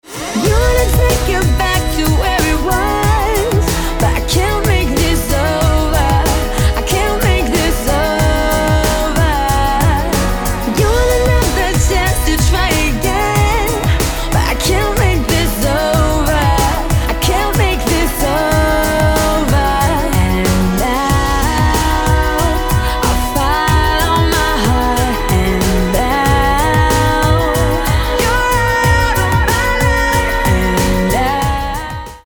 19-ročná anglická speváčka a herečka